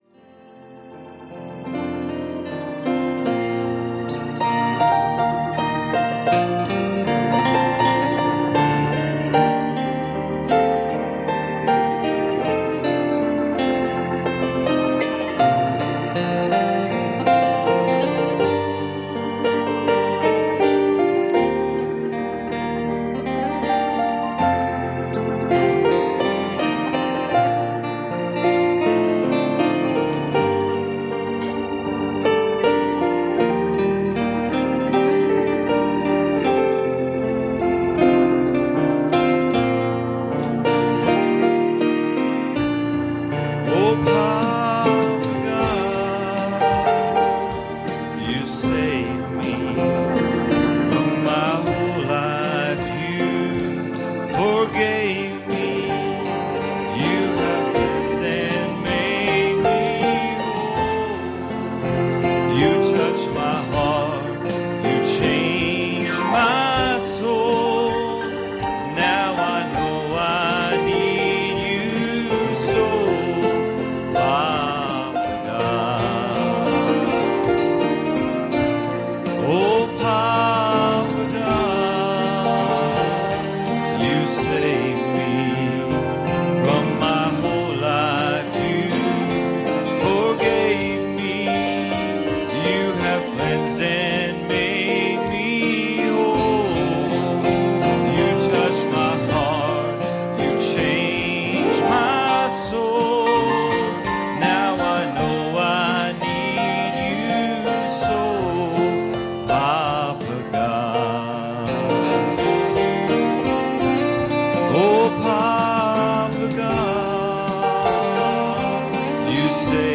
Passage: Hebrews 10:14 Service Type: Sunday Morning